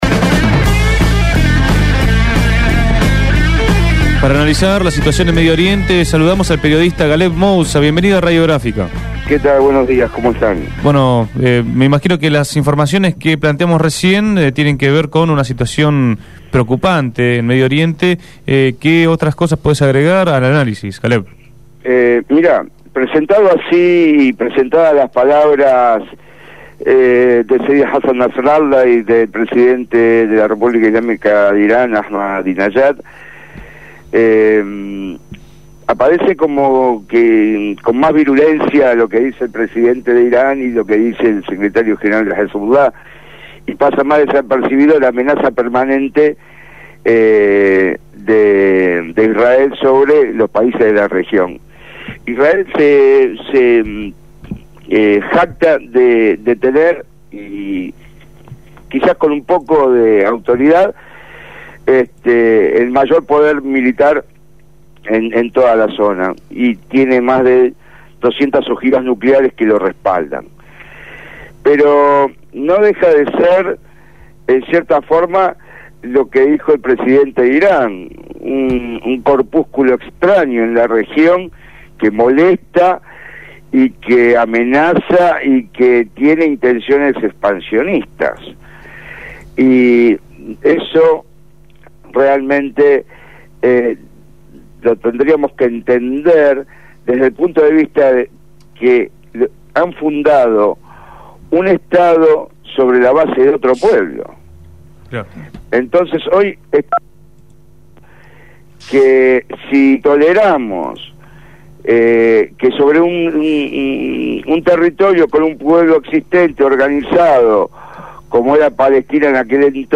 habló en Punto de Partida.